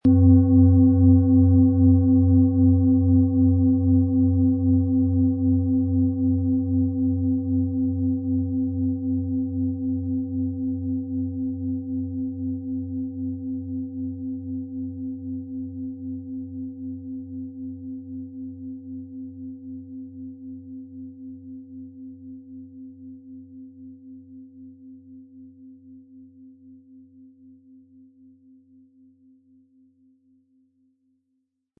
Planetenton
Handgearbeitete Jupiter Klangschale.
MaterialBronze